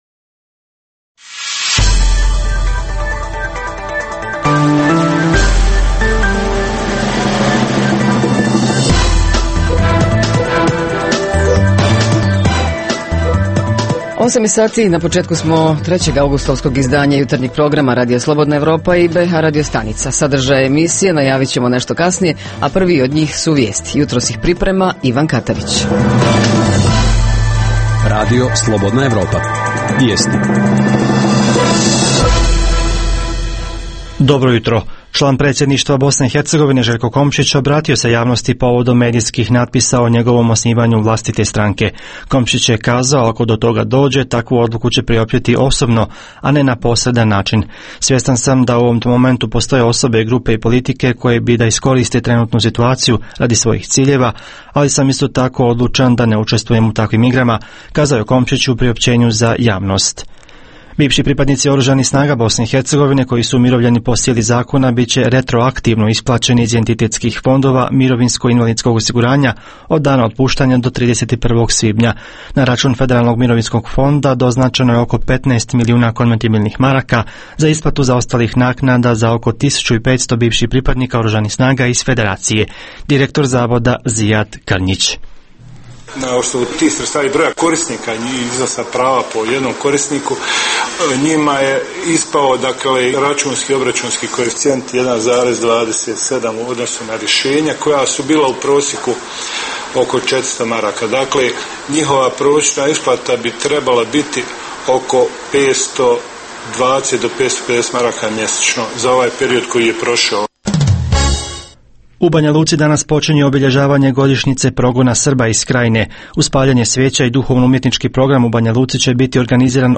- Poljoprivrednici oba entiteta najavljuju vruću jesen i spremnost na formiranje jedinstvene bh poljoprivredne komore. - Vjenčanja i razvodi – to je tema jutra koju su obradili dopisnici iz Konjica, Bihaća i Tuzle. - Olimpijska hronika, rubrika je koju emitujemo svakodnevno (osim nedjelje) tokom trajanja Olimpijade, a redovna rubrika petkom je Za zdrav život.